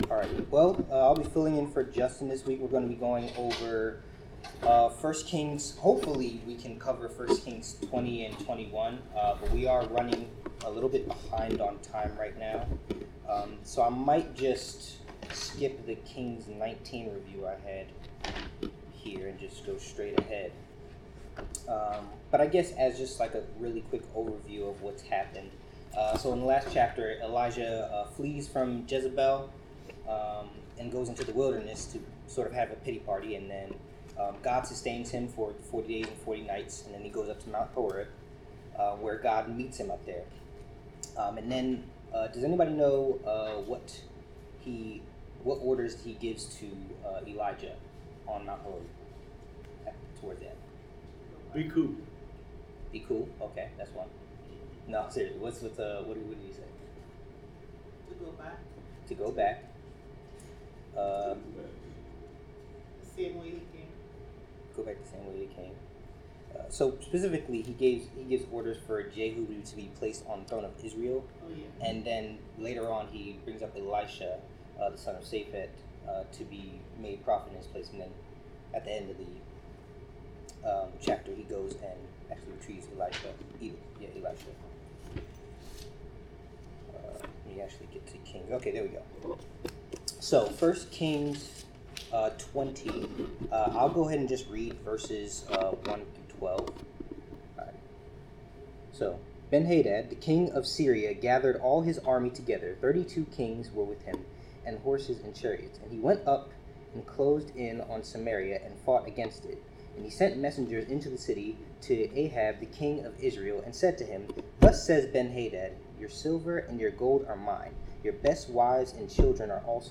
1 Kings 20-21 Service Type: Bible Class Topics